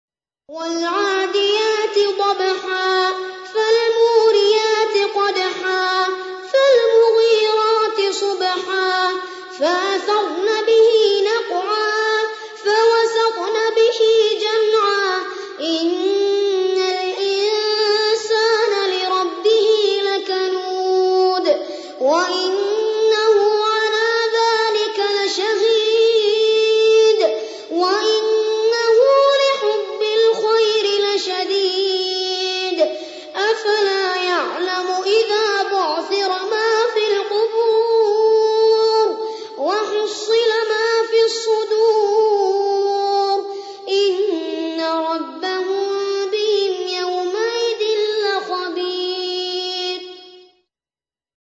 قارئ معتمد رواية ورش عن نافع
أحد أشهر قراء القرآن الكريم في العالم الإسلامي، يتميز بجمال صوته وقوة نفسه وإتقانه للمقامات الموسيقية في التلاوة.